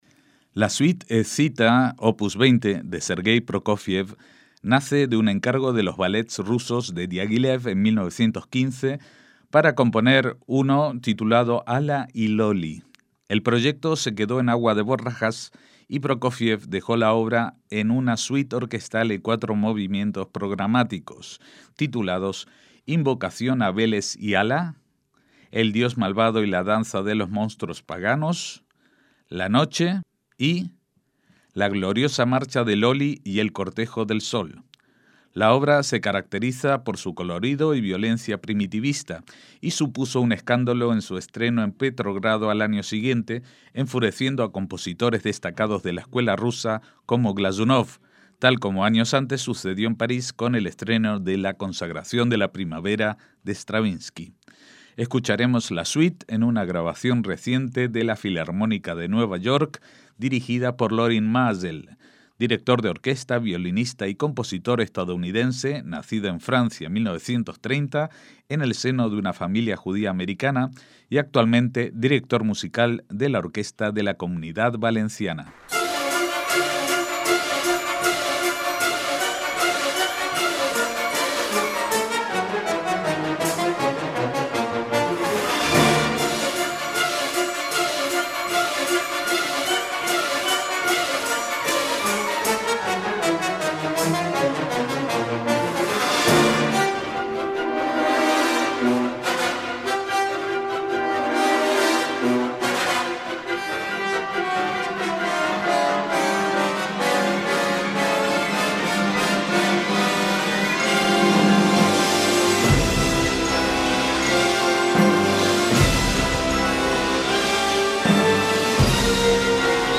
MÚSICA CLÁSICA - La Suite escita de Prokofiev tiene una historia que comienza como un ballet encargado en 1915 por Diaghilev (titulado Ala et Lolly), pero rechazado después de que determinara que la música no era adecuada para sus Ballets Rusos.